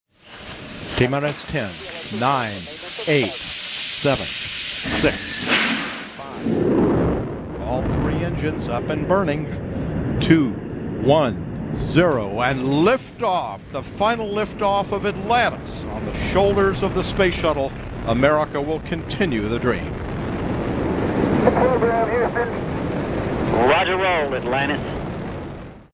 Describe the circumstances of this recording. Space Shuttle Atlantis Countdown & Liftoff